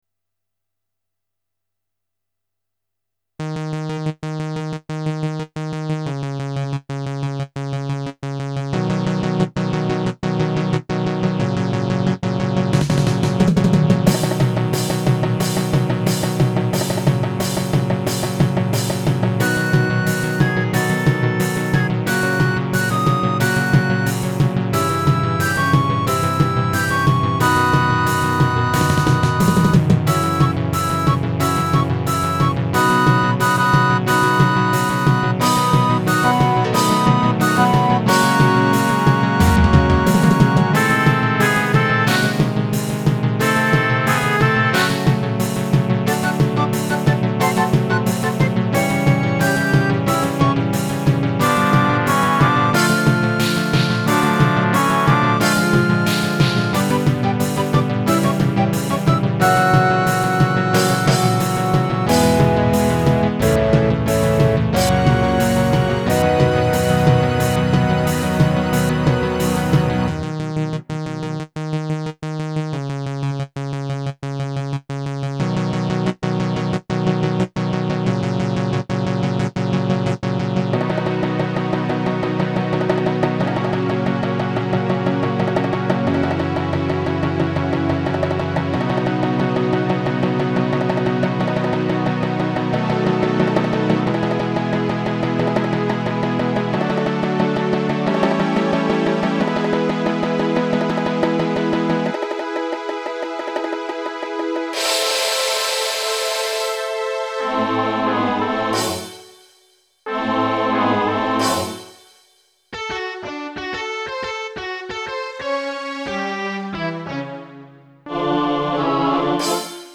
Tuneful and catchy.